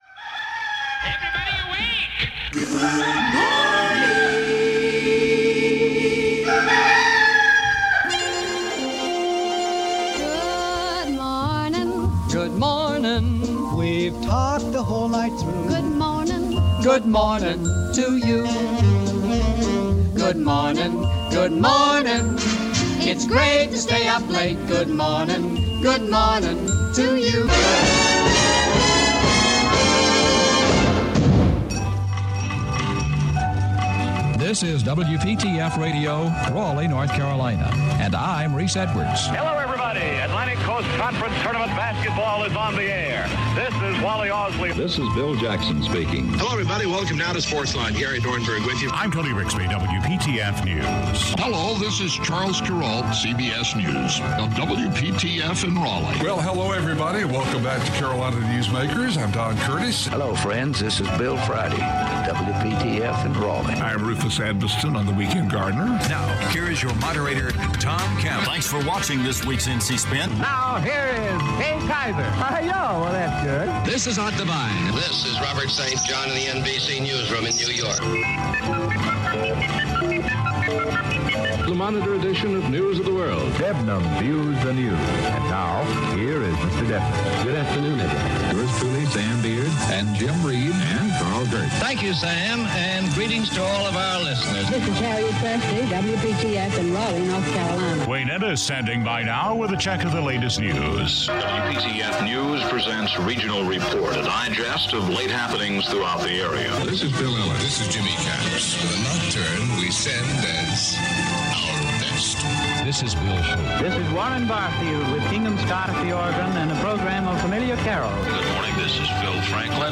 Weekend Gardener - Live from Crossroads Corn Maze, Wendell (Part 3 Of 3)